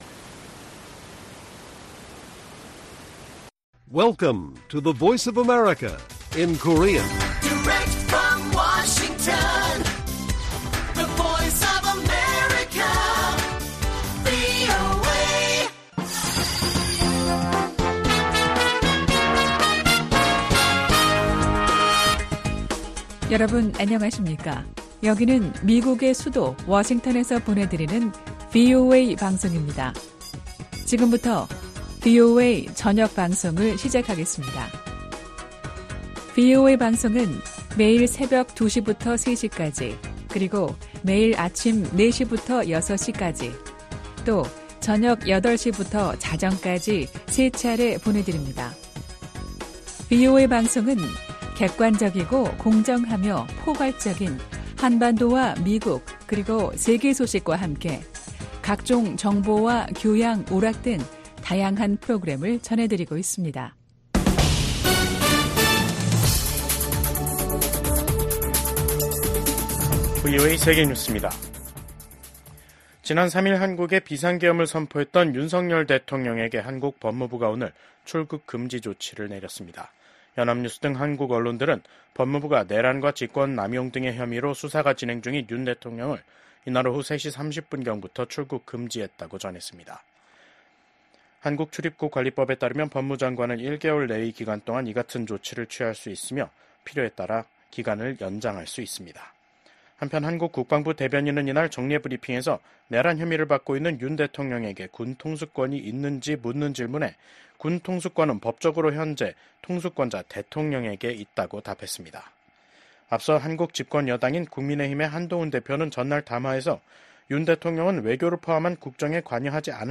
VOA 한국어 간판 뉴스 프로그램 '뉴스 투데이', 2024년 12월 9일 1부 방송입니다. 윤석열 한국 대통령이 비상계엄 선포 행위로 내란 혐의 피의자가 되면서 국정 공백에 대한 우려가 커지고 있습니다. 미국 정부는 윤석열 대통령의 단호한 대북·대중 정책과 우호적 대일 정책이 탄핵 사유로 언급된 것과 관련해, 한국 민주주의 체제의 원활한 운영이 중요하다는 입장을 재확인했습니다.